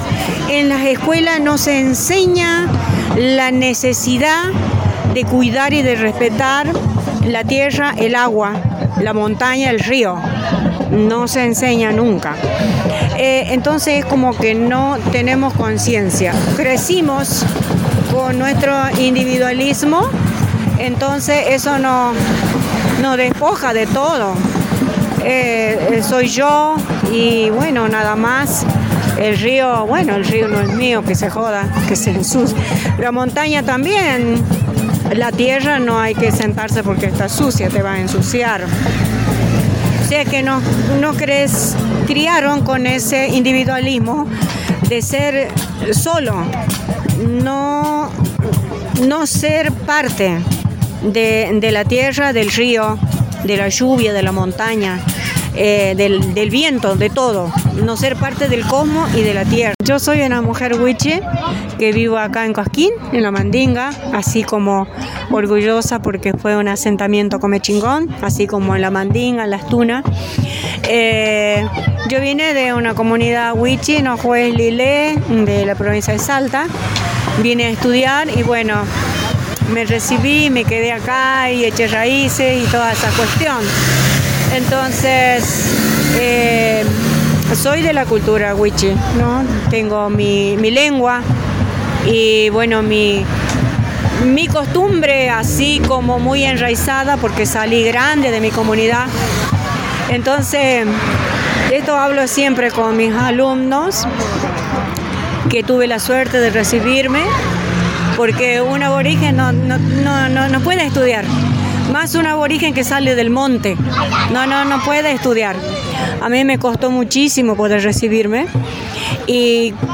Caminata artística por el agua
El día 22 de marzo, en el marco del Día Mundial del Agua, se realizó la “Caminata Artística por el Agua”.